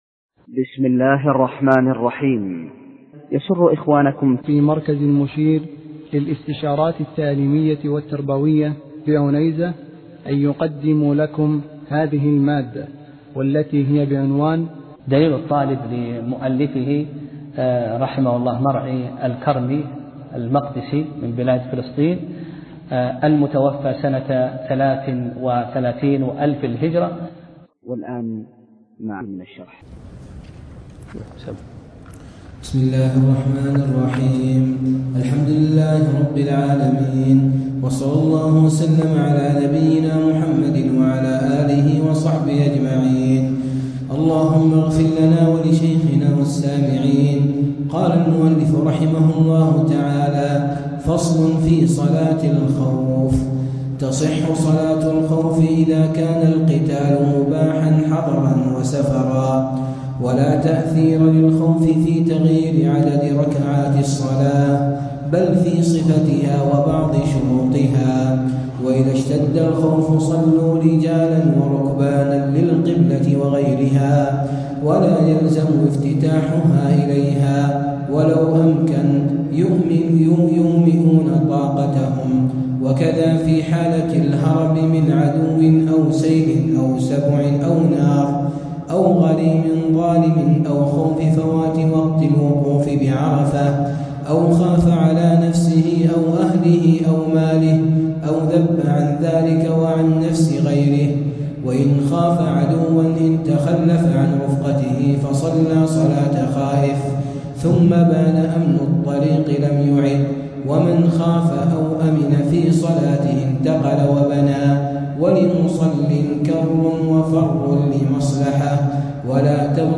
درس (18) : فصل في صلاة الخوف